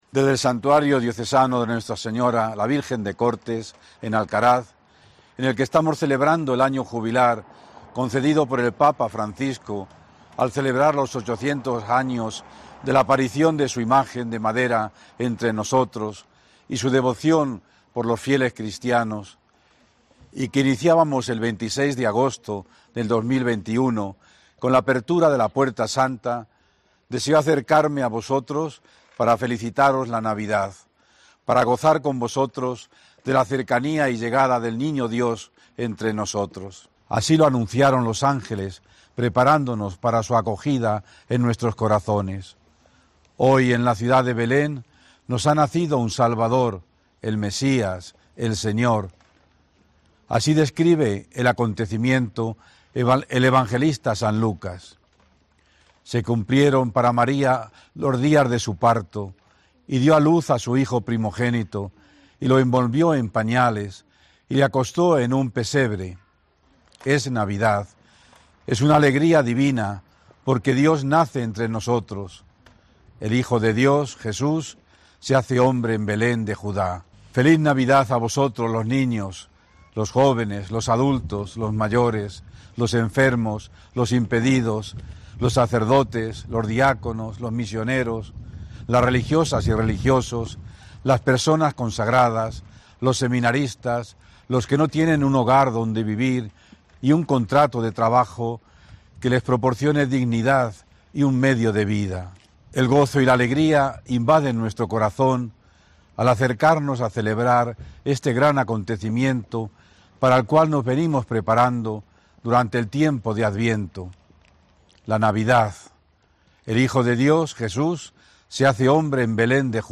Desde el Santuario de Cortes, mensaje episcopal del Obispo de Albacete
Mensaje de Navidad del obispo de Albacete, Ángel Fernández Collado
En pleno Año Jubilar y rodeado de niños, don Ángel Fernández Collado, obispo de Albacete, ha lanzado a la Diócesis su tradicional mensaje navideño.